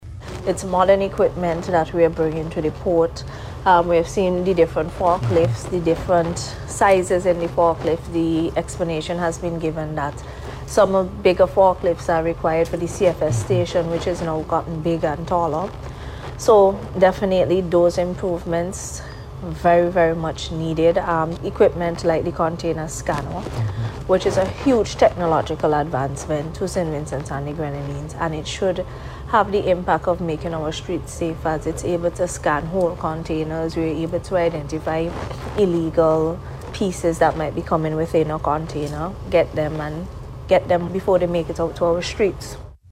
And, speaking to the API, Minister of Urban Development and Seaport, Benarva Browne said she is elated about the new facility, which is being hailed as a transformative infrastructure project for St. Vincent and the Grenadines.